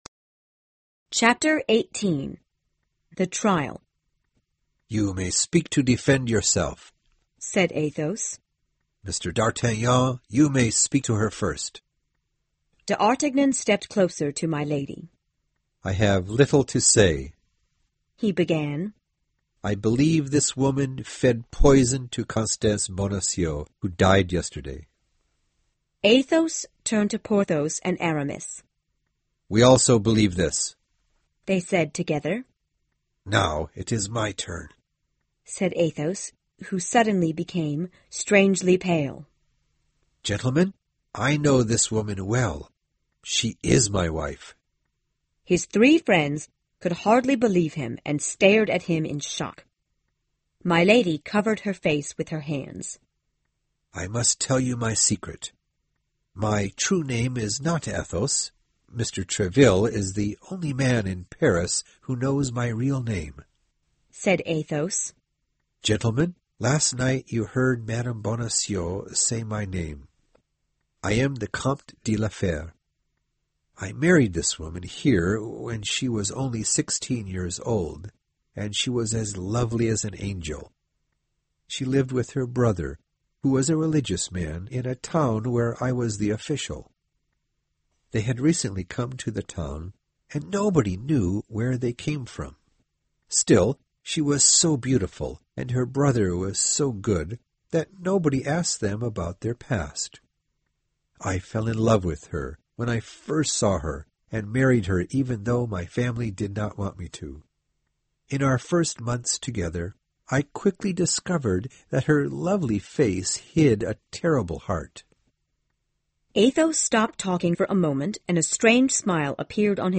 有声名著之三个火枪手 18 听力文件下载—在线英语听力室